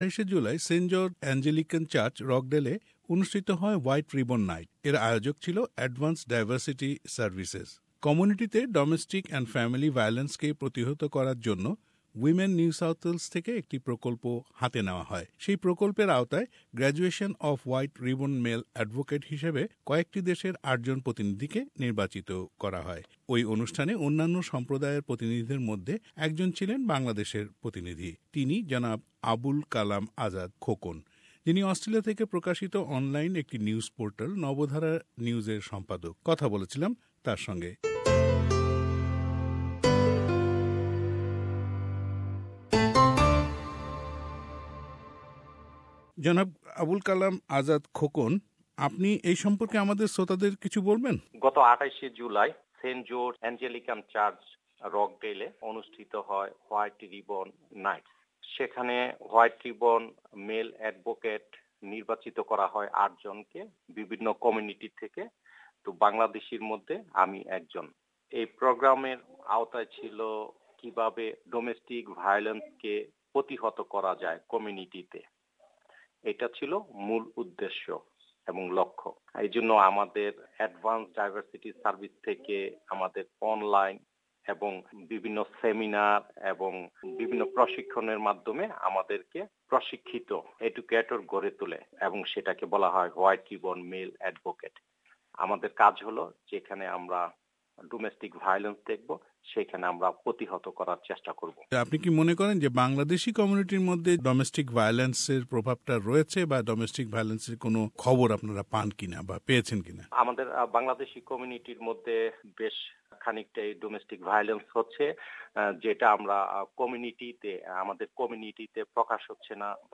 White Ribbon night : Interview